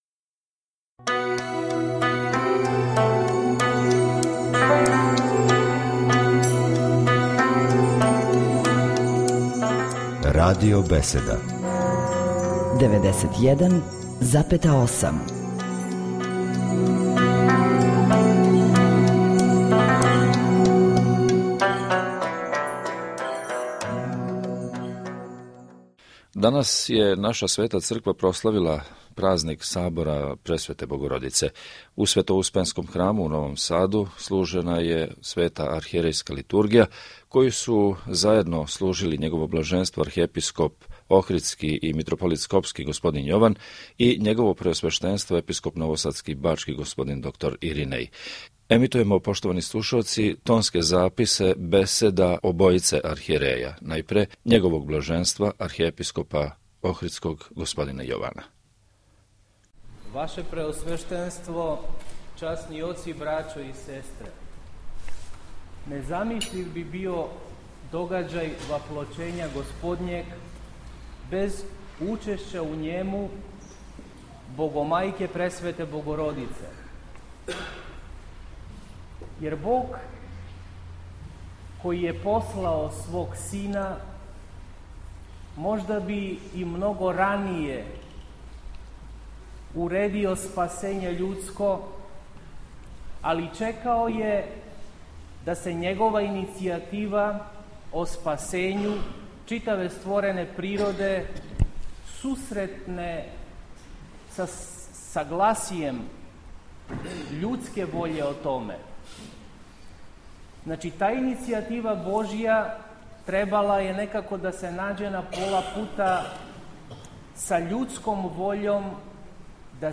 Архиепископ охридски Јован служио свету Литургију у Новом Саду
На празник Сабора Пресвете Богородице, Архиепископ охридски Господин Јован началствовао је евхаристијским сабрањем у Светоуспенском храму у Новом Саду.
Његовом Блаженству саслуживали су Епископ бачки Господин Иринеј, свештенство Богодоричиног храма и многобројни верни народ.